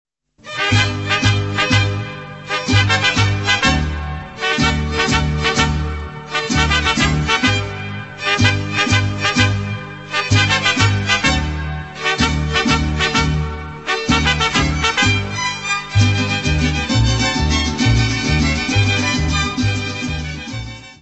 : stereo; 12 cm + folheto
Área:  Tradições Nacionais